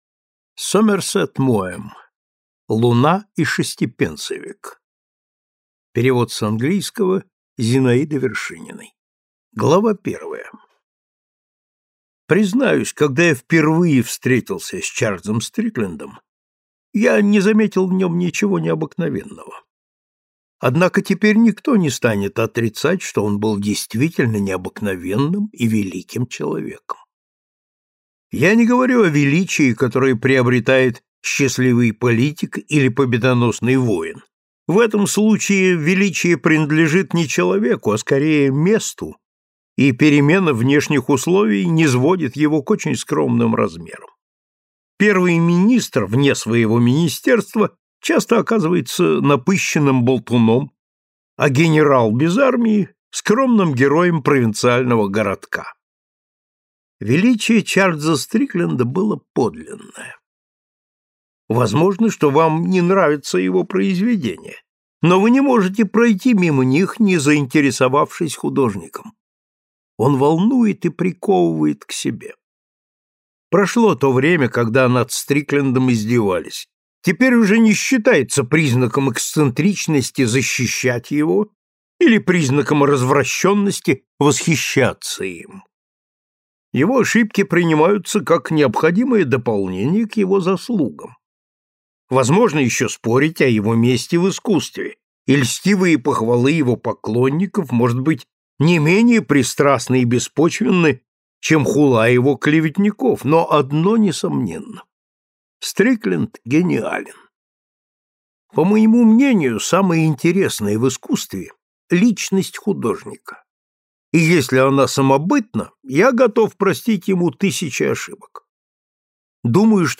Аудиокнига Луна и шестипенсовик | Библиотека аудиокниг